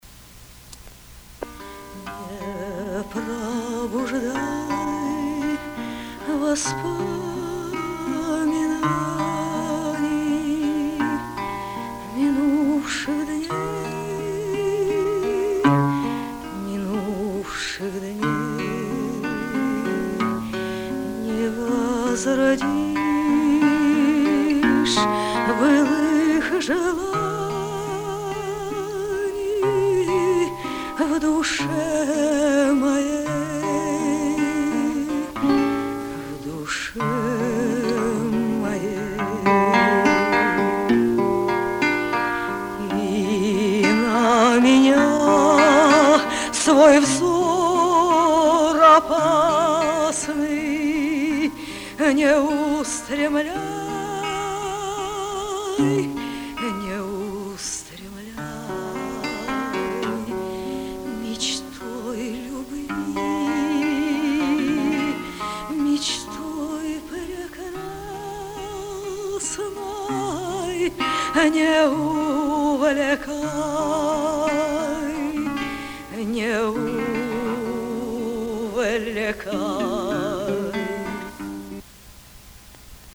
Романсы на стихи Ф.И. Тютчева: